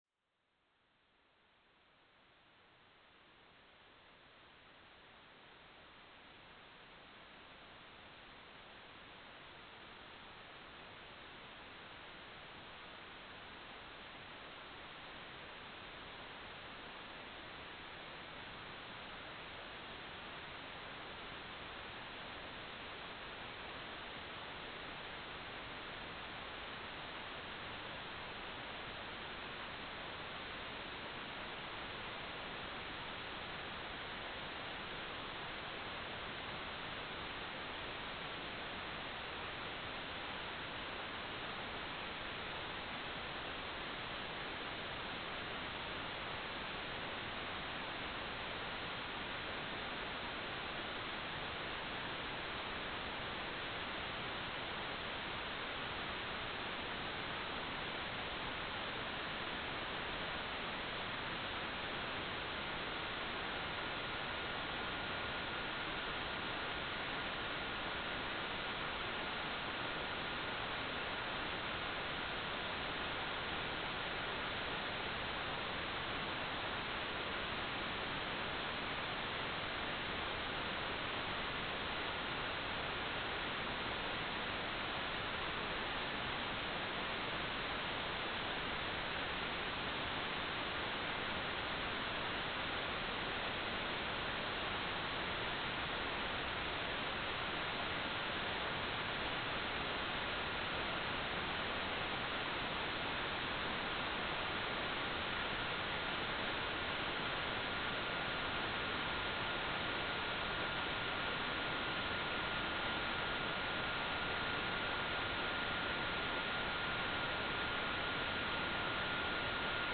"transmitter_mode": "CW",